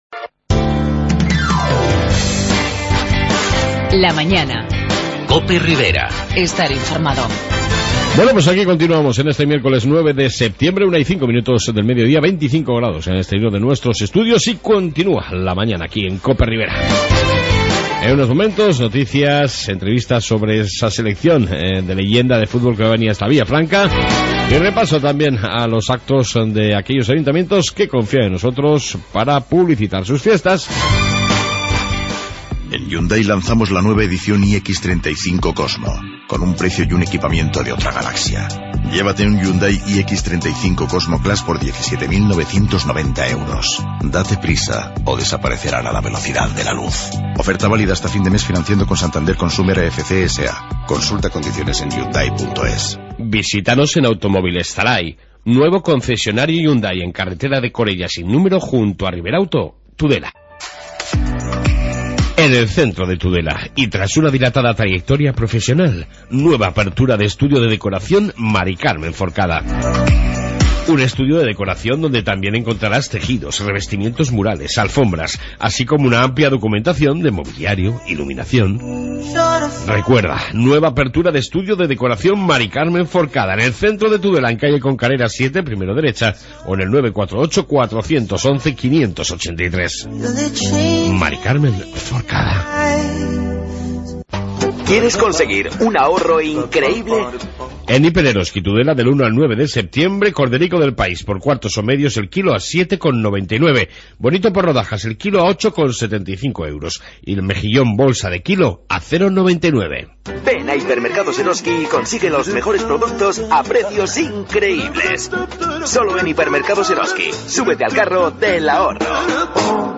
AUDIO: En esta 2 Parte Noticias Riberas y Entrevista sobre el trofeo donde jugarán este domingo La Selección Española de Fútbol,...